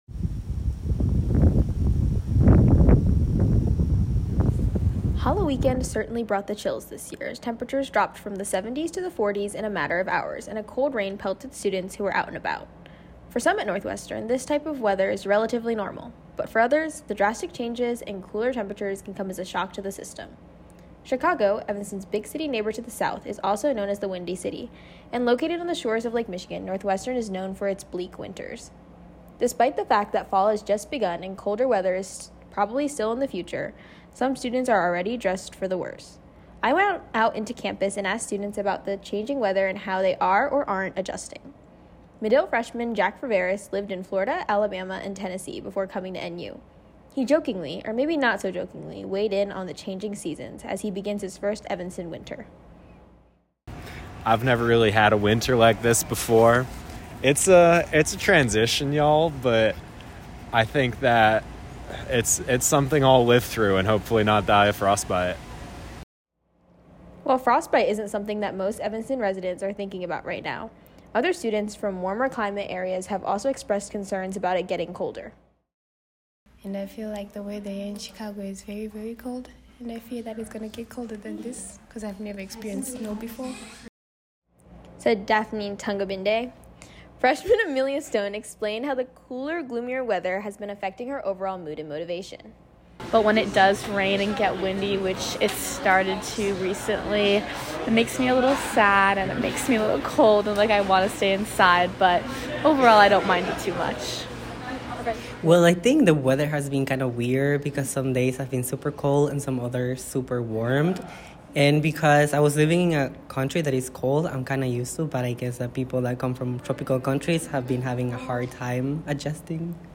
[natural sounds: wind in leaves]
[more wind sounds]